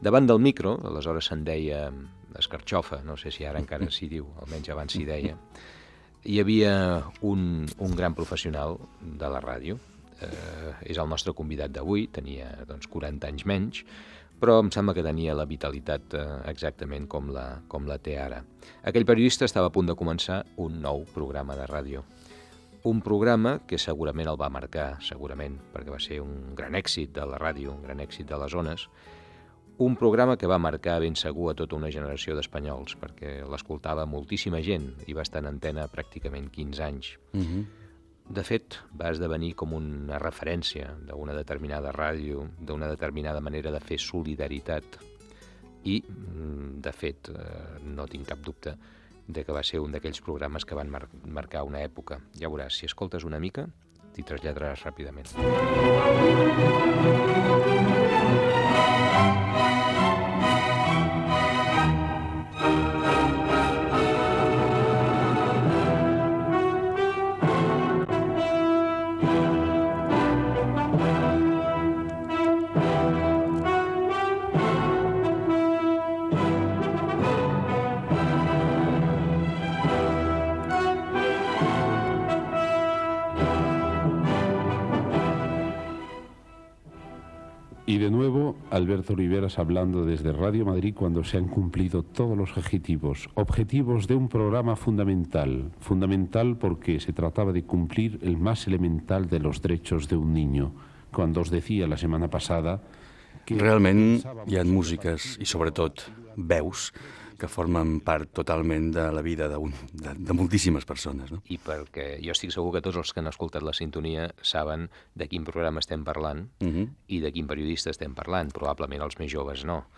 Amb el periodista i locutor Alberto Oliveras recorden el programa solidari "Ustedes son formidables" a la Cadena SER, que va començar el 18 d'octubre de 1960.
Entreteniment
Fragment extret de l'arxiu sonor de COM Ràdio.